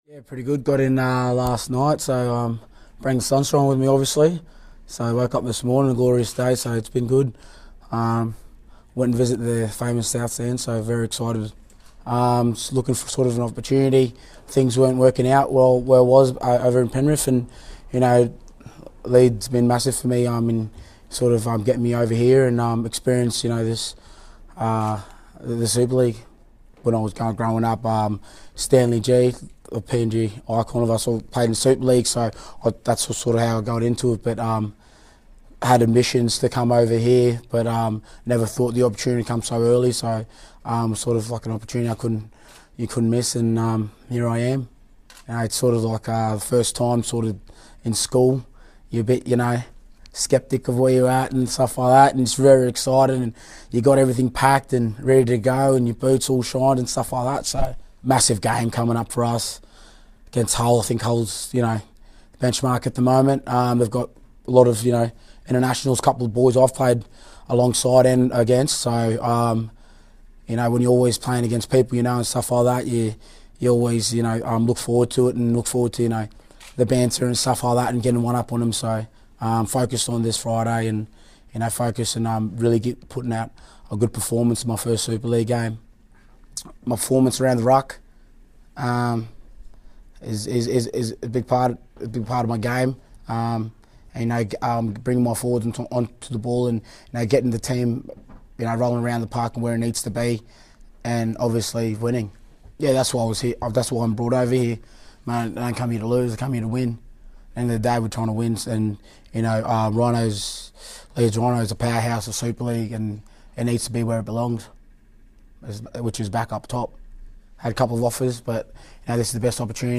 New Leeds Rhinos signing James Segeyaro spoke to the press on his arrival at Headingley Carnegie